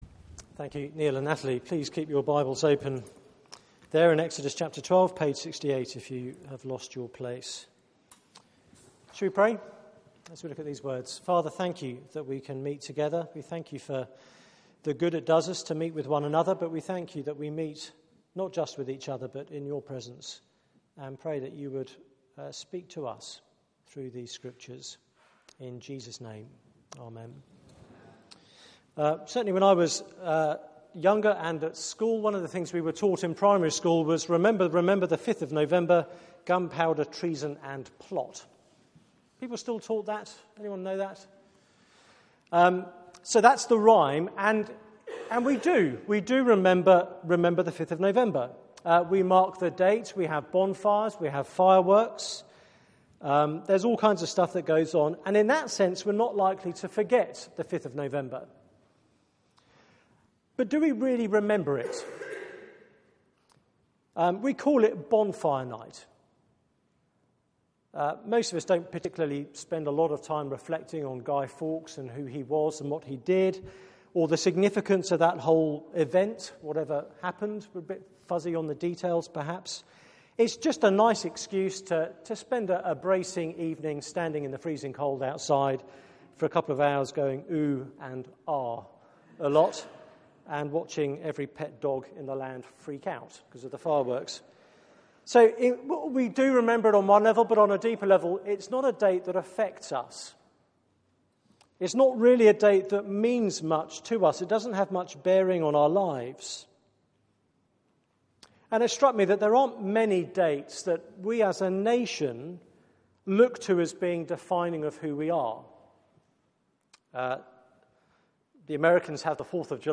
Passage: Exodus 12:1-30 Service Type: Weekly Service at 4pm